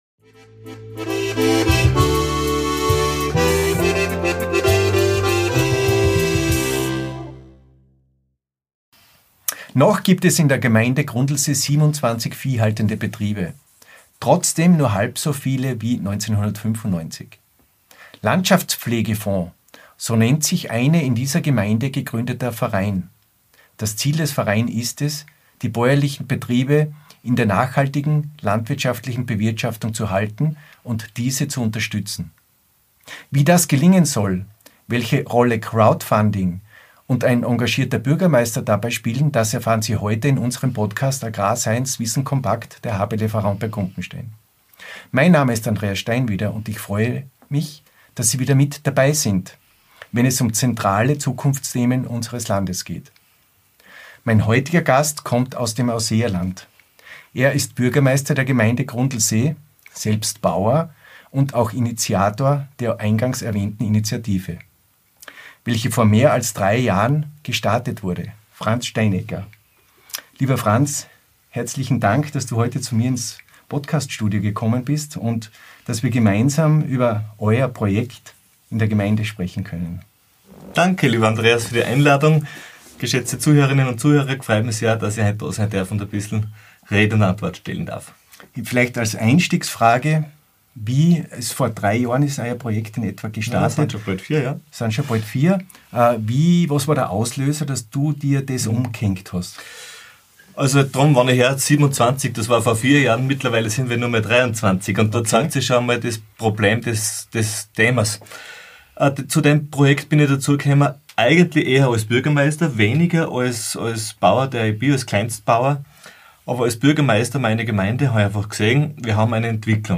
Wir sprechen über den Verein „Landschaftspflegefonds“, der sich für nachhaltige Landwirtschaft einsetzt, und erfahren, wie Crowdfunding und der Einsatz des Bürgermeisters dazu beitragen. Der heutige Podcast-Gast ist Franz Steinegger, Bürgermeister der Gemeinde Grundlsee, Bauer und Initiator der genannten Initiative.